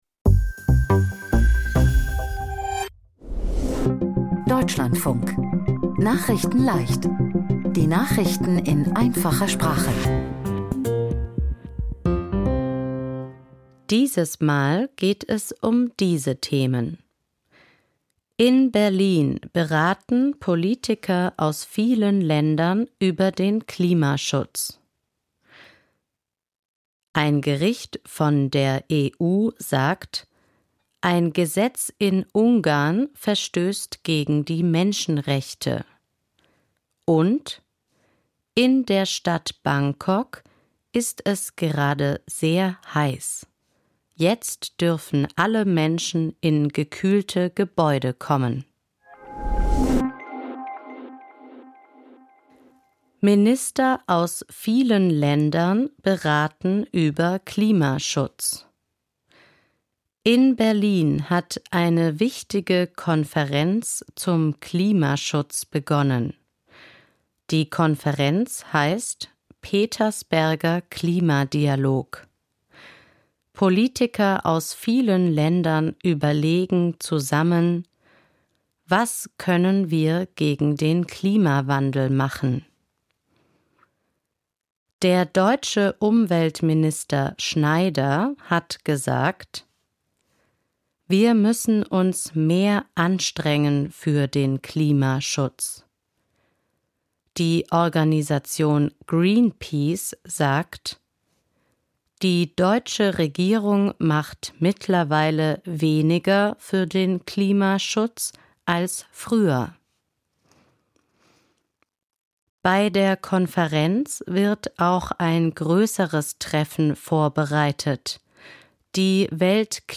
Nachrichten in Einfacher Sprache vom 21.04.2026